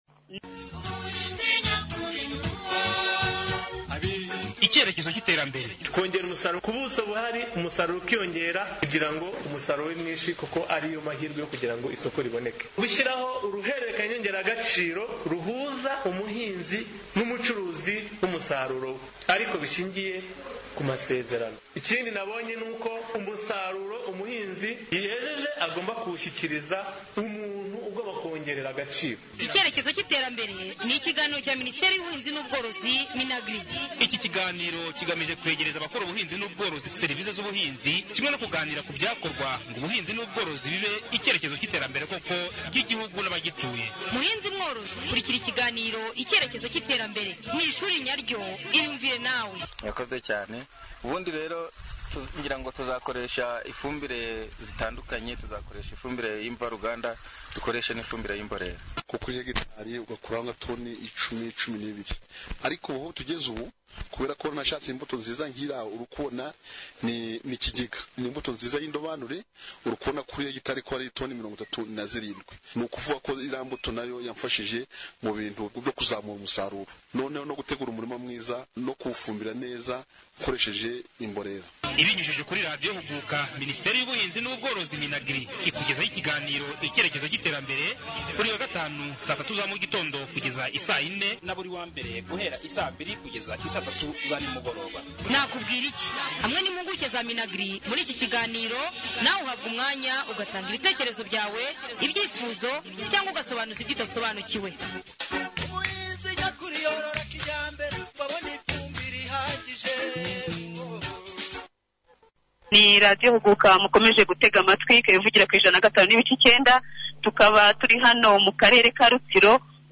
02.Ikiganiro ku buryo bwo Kuvugurura urutoki